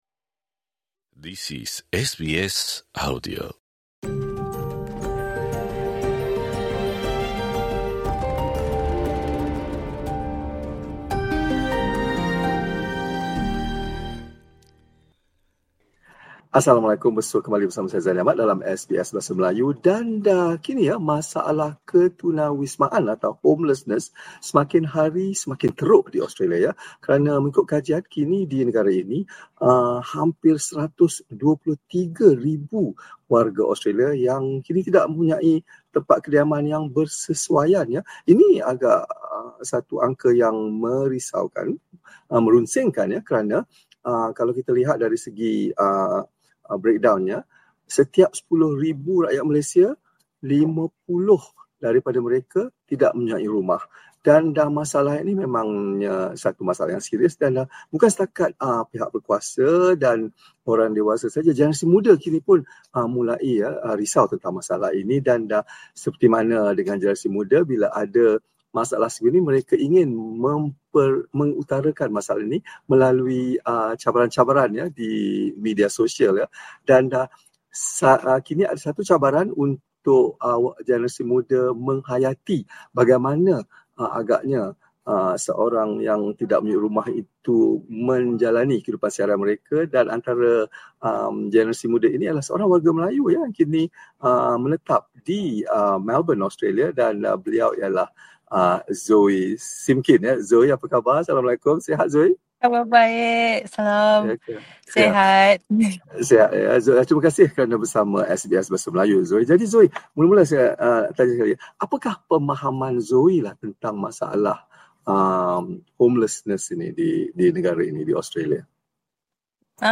perbualan